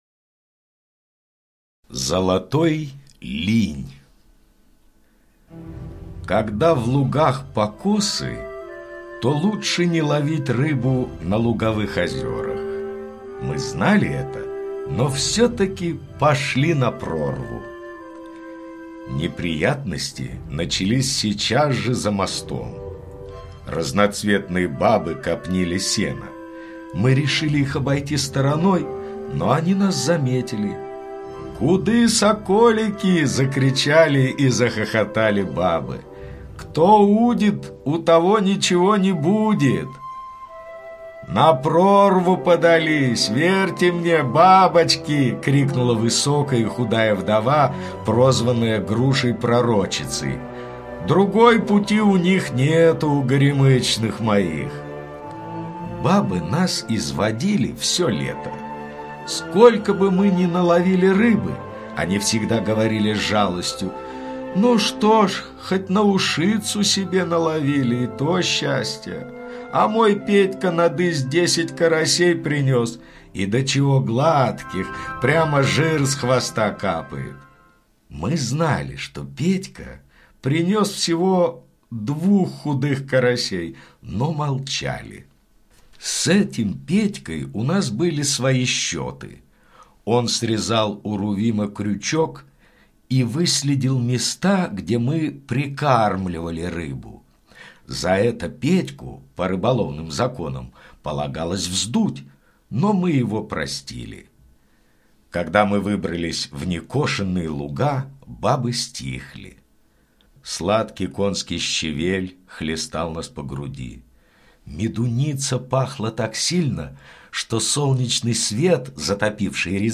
Золотой линь - аудио рассказ Паустовского К. История про удачную рыбалку, когда автор с другом поймали огромного линя.